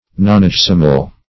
Nonagesimal \Non`a*ges"i*mal\, n. (Astron.)